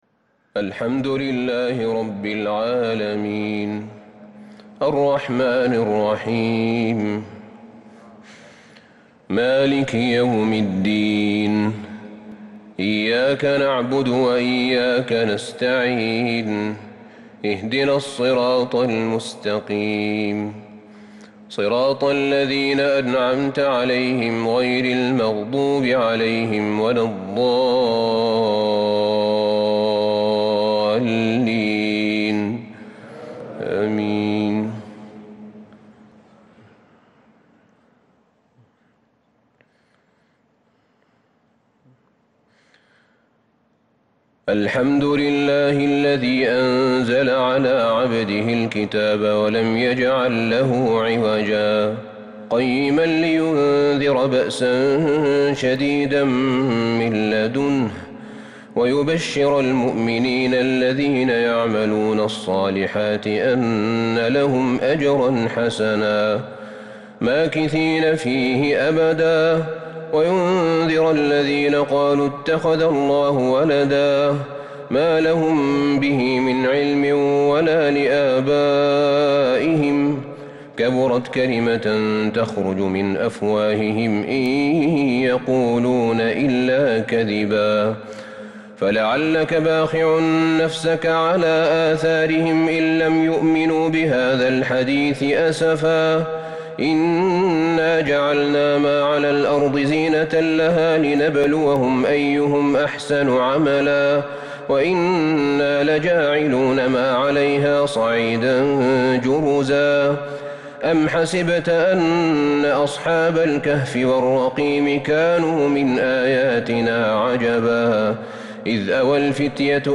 صلاة التهجد | ليلة 30 رمضان 1442 l سورة الكهف | tahajud prayer The 30th night of Ramadan 1442H | from surah Al-Kahf > تراويح الحرم النبوي عام 1442 🕌 > التراويح - تلاوات الحرمين